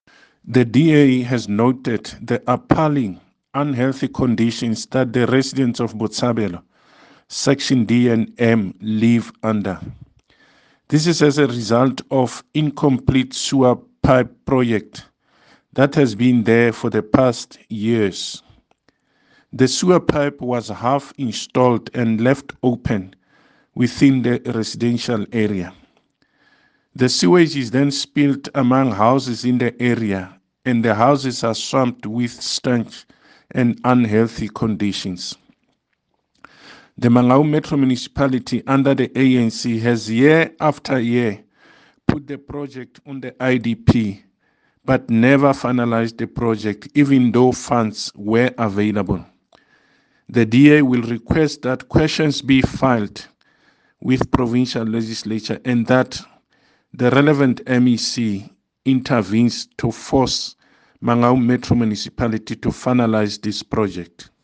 Sesotho soundbites by Cllr David Masoeu.